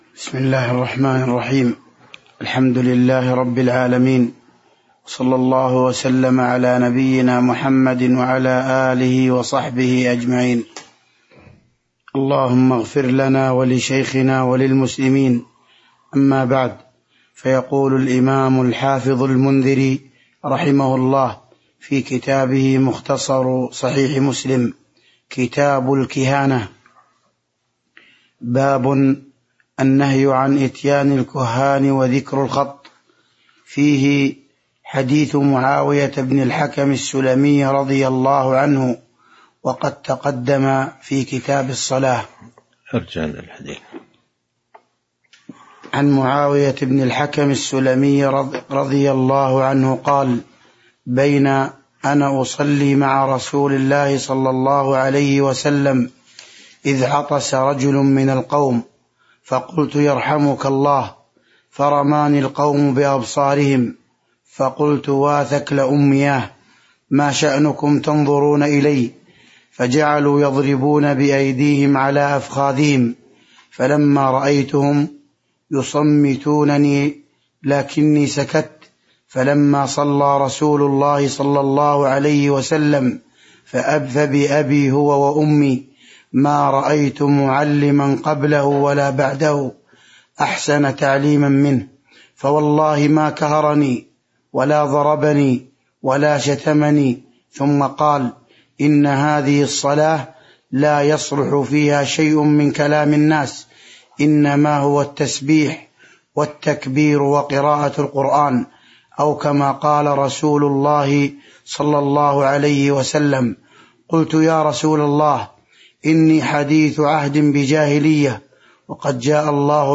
تاريخ النشر ٢٧ رجب ١٤٤٣ هـ المكان: المسجد النبوي الشيخ